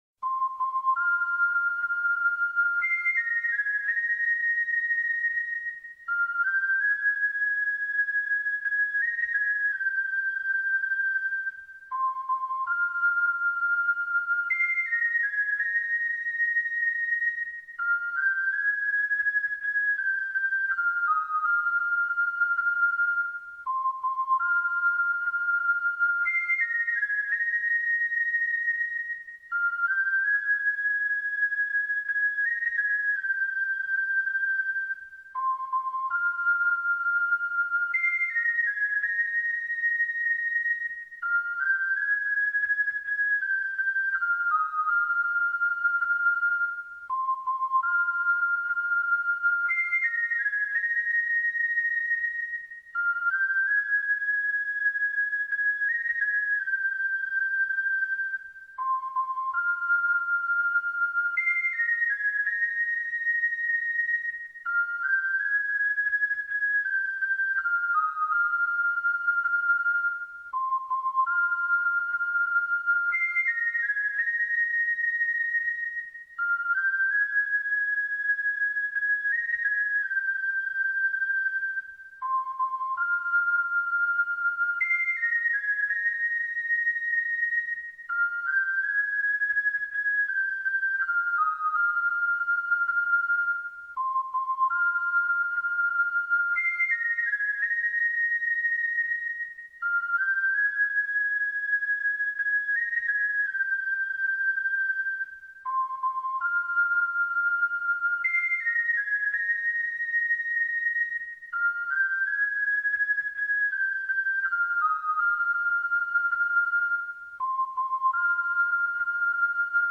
ok aber warum ist deine Stimme so anders xD